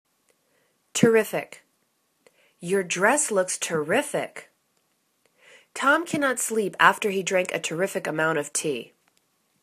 ter.rif.ic     /tə'rifik/    adj